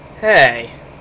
Hey Sound Effect
Download a high-quality hey sound effect.